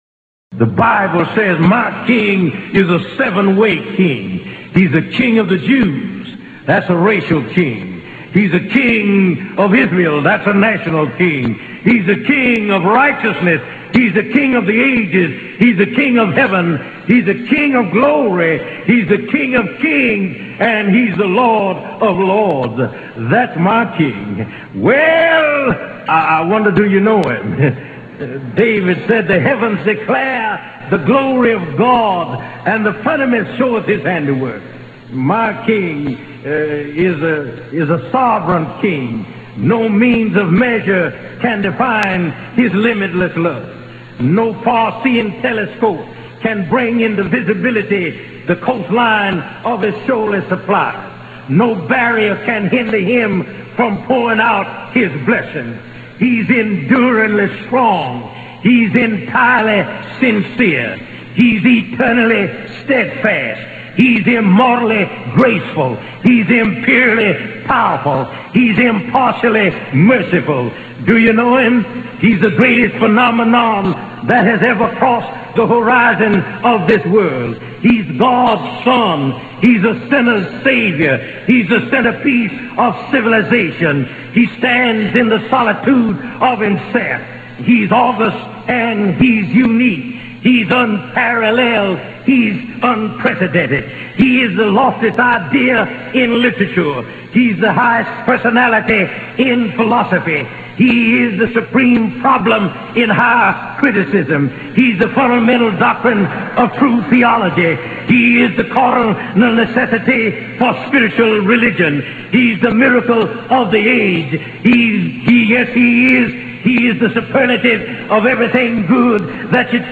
(Sermon)
Excerpt from a sermon by S.M. Lockridge, entitled "That's My King"